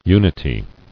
[u·ni·ty]